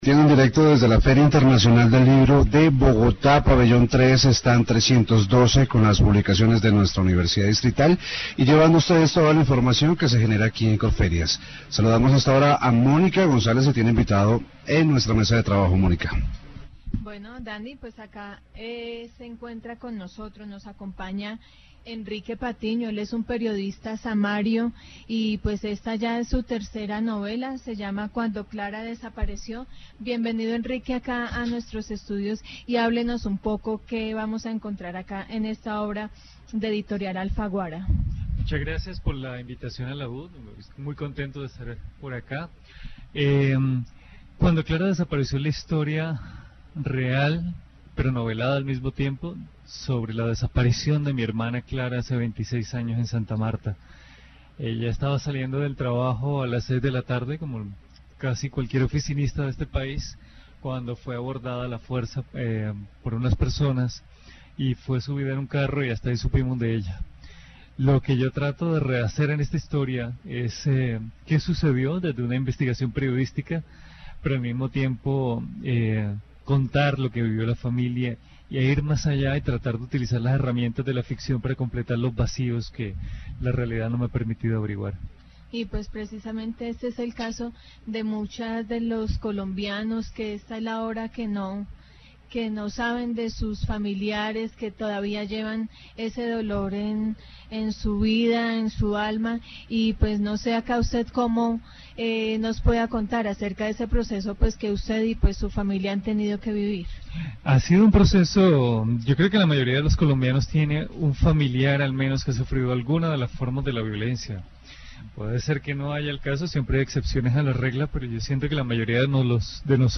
Programas de radio , Bogotá (Colombia) -- Grabaciones sonoras